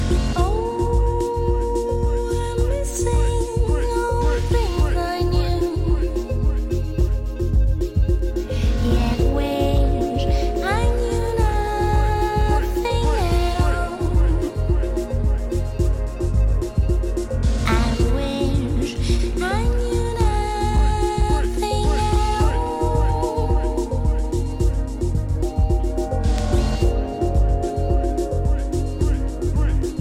Classic Trip-Hop Album !!!